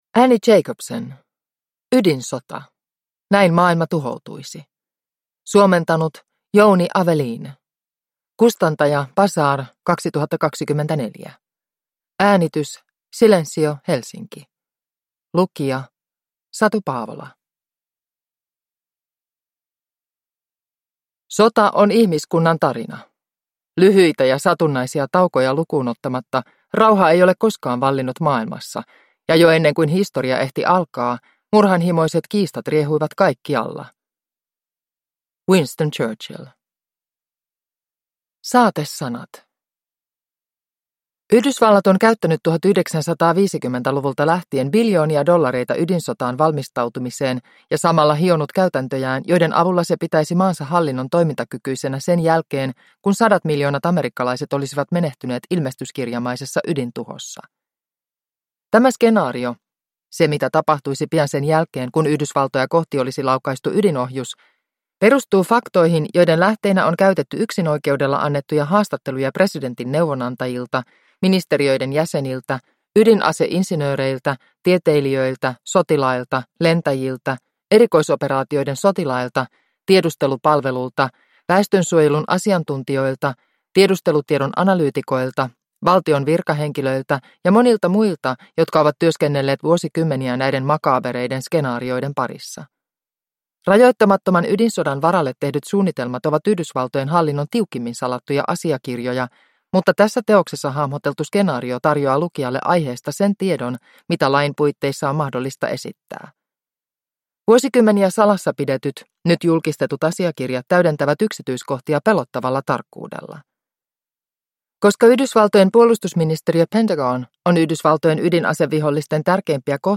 Ydinsota (ljudbok) av Annie Jacobsen